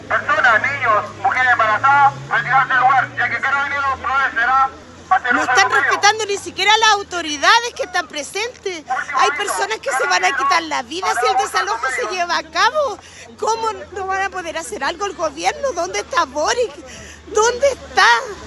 En el lugar hubo enfrentamientos con Carabineros, quienes indicaron que, de no haber colaboración por parte de las personas, usarían sus medios para llevar adelante lo ordenado por la Suprema.
desalojo-toma-curanilahue.mp3